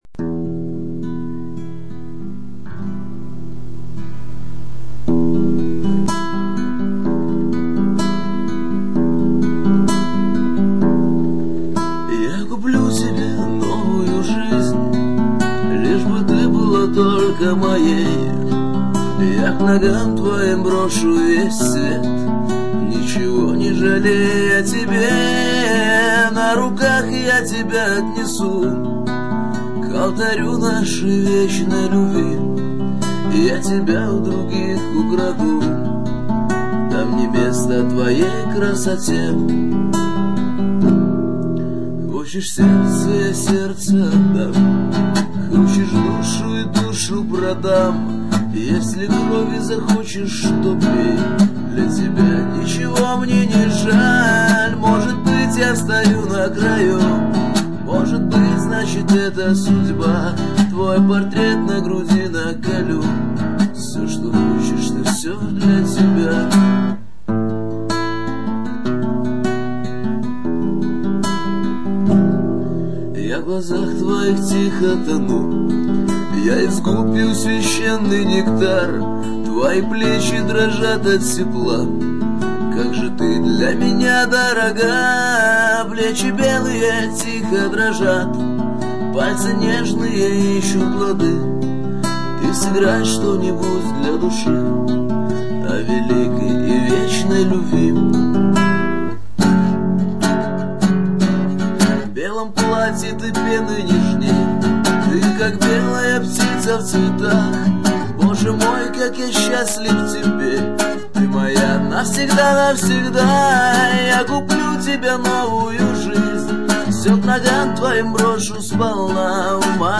Dvorovye_pesni_Ya_kuplyu_tebe_novuyu_zhizn.mp3